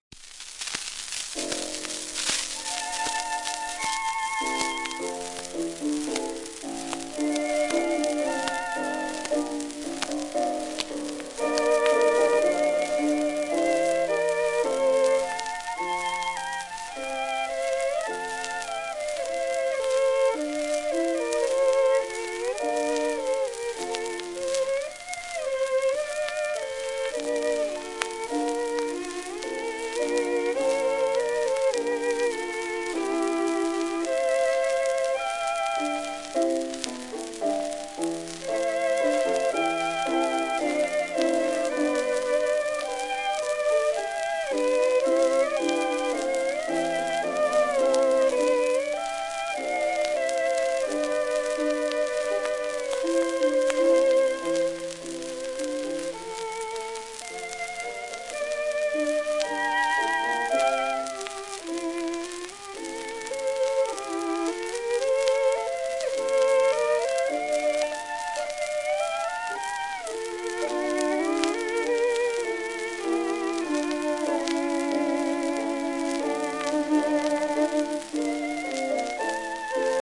イゾルデ・メンゲス(Vn:1893-1976)&
ウィリアム・プリムローズ(Vn:(vn:1904-82))
盤質A-B+ *キズ、小キズ、サーフェイスノイズ
２ー３箇所音に出るキズあり
1920年代前半の録音。